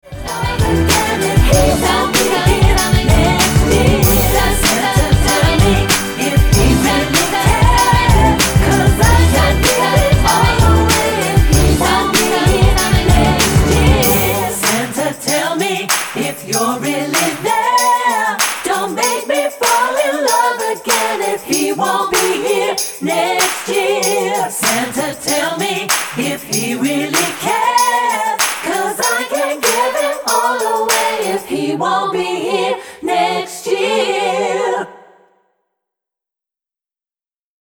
--> MP3 Demo abspielen...
Tonart:G mit Chor